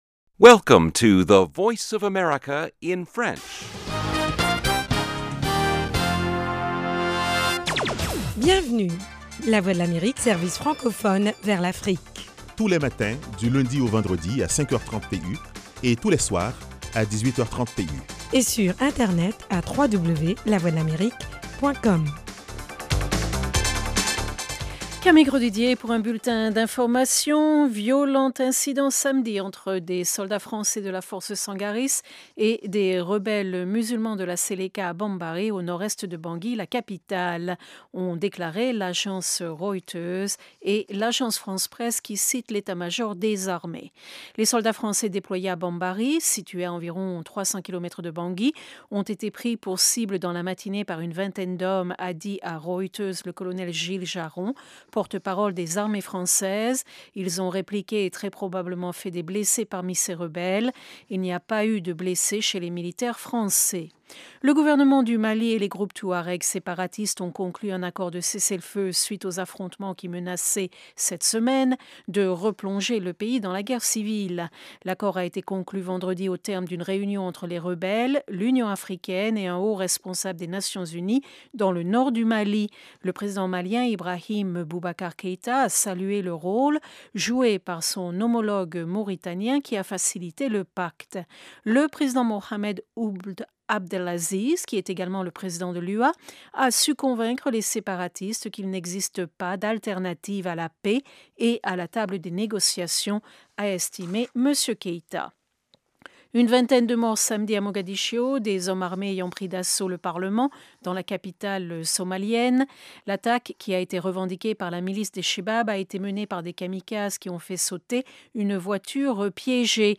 Le magazine vous tient aussi au courant des dernières découvertes en matière de technologie et de recherche médicale. Carrefour Santé et Sciences vous propose aussi des reportages sur le terrain concernant les maladies endémiques du continent : paludisme, sida, polio, grippe aviaire…